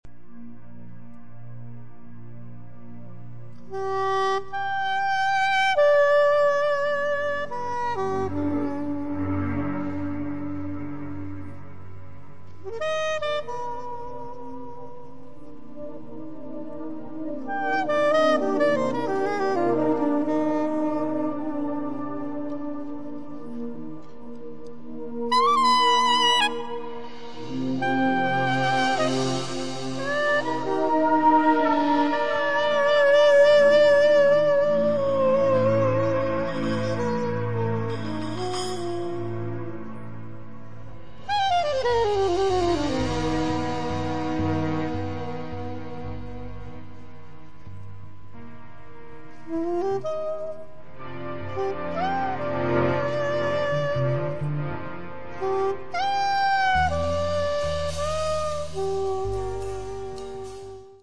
tromba e flicorno
sax, clarinetto basso
trombone, tuba
bombardino
chitarra
contrabbasso
batteria